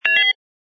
sfx_ui_react_request01.wav